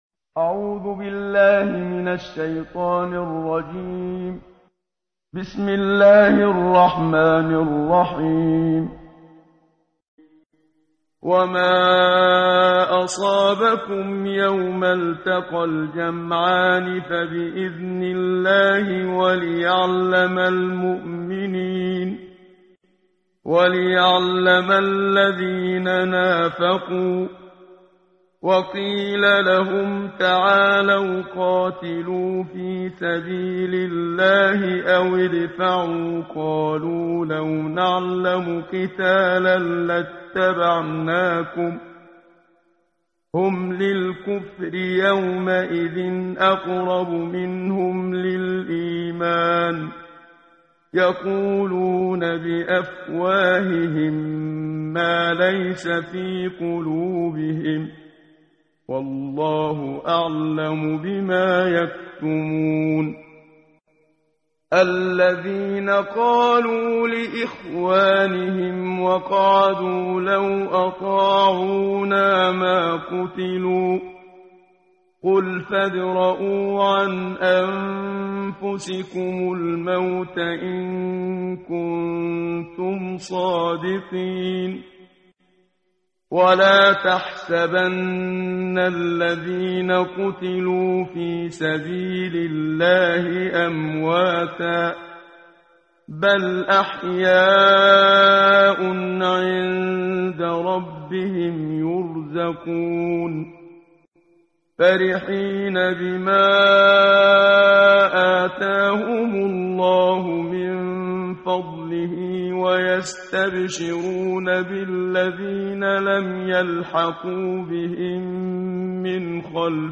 قرائت قرآن کریم ، صفحه 72، سوره مبارکه آلِ عِمرَان آیه 173 تا 165 با صدای استاد صدیق منشاوی.